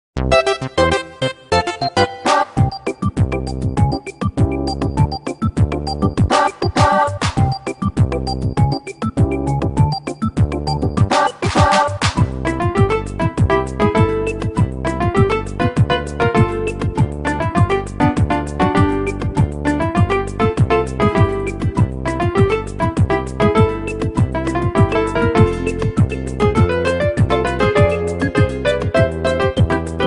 Copyrighted music sample